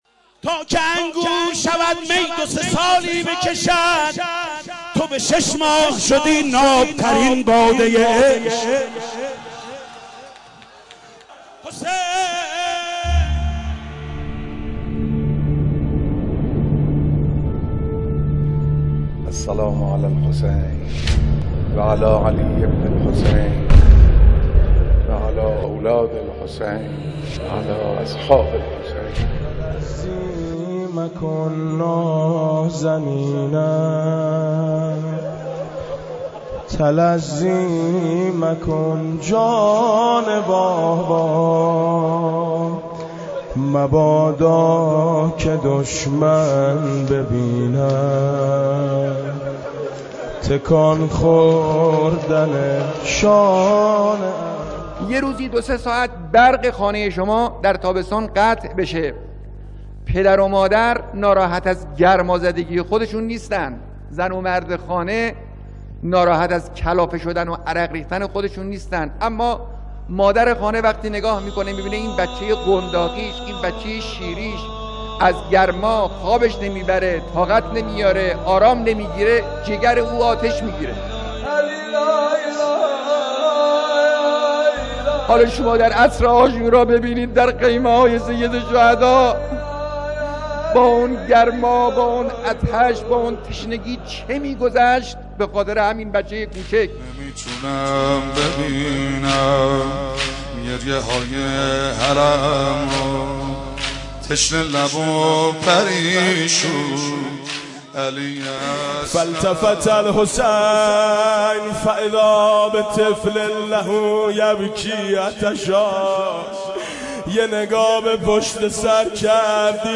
روضه حضرت علی اصغر (ع).mp3